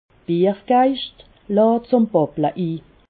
Haut Rhin
Ville Prononciation 68
Ribeauvillé